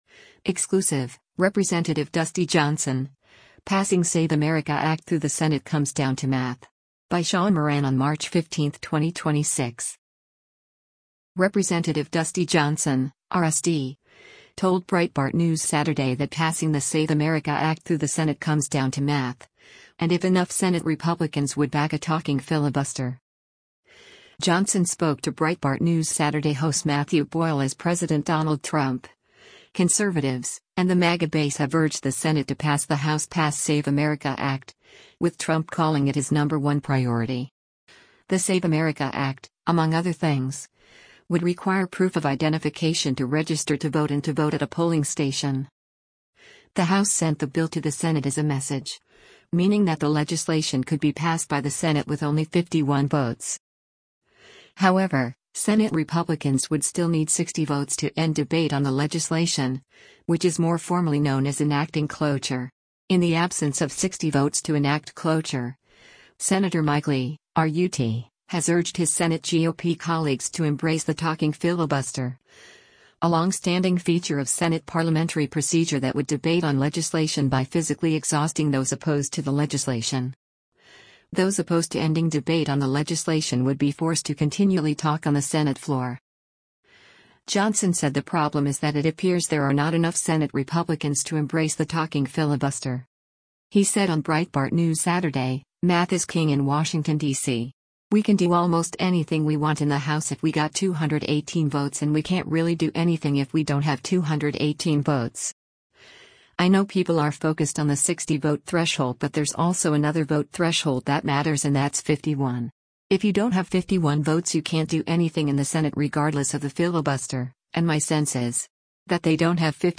Rep. Dusty Johnson (R-SD) told Breitbart News Saturday that passing the SAVE America Act through the Senate comes down to “math,” and if enough Senate Republicans would back a “talking filibuster.”
Breitbart News Saturday airs on SiriusXM Patriot 125 from 10:00 a.m. to 1:00 p.m. Eastern.